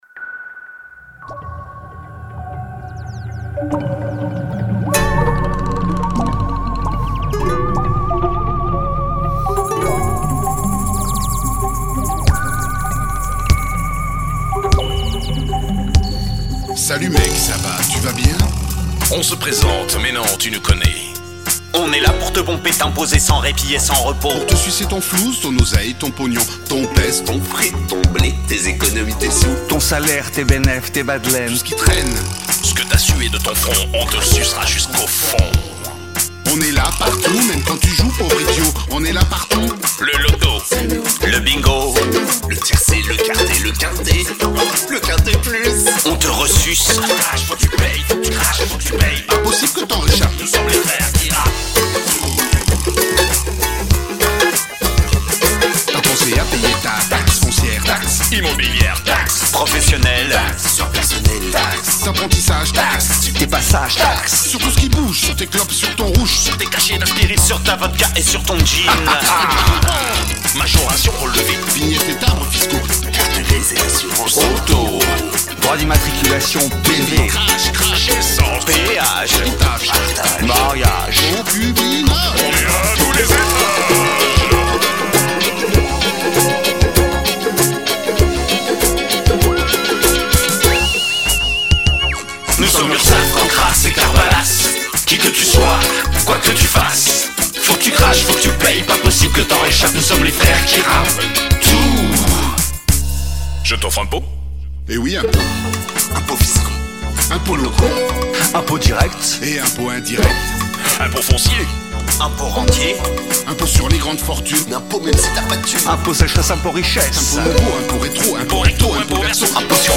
Gb Andante 2018 7 écoutes
# ukuleleCover